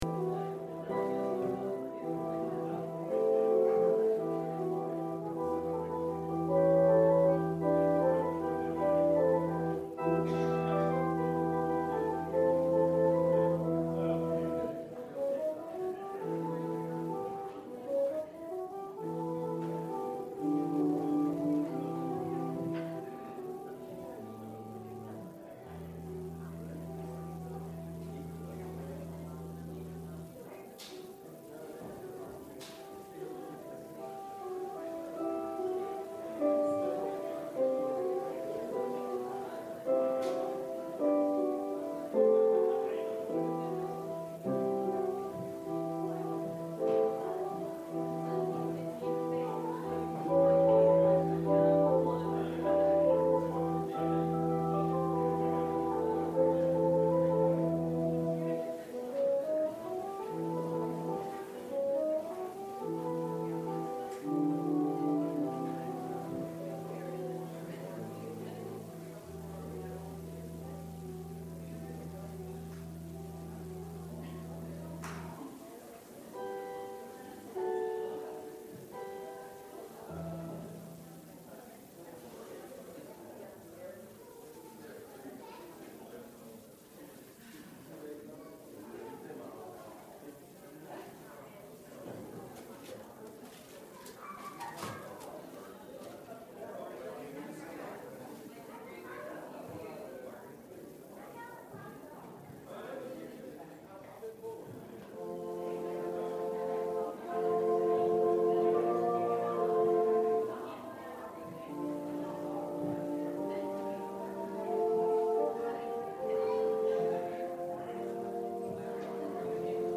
Philippians Service Type: Sunday Worship Merry Christmas!!!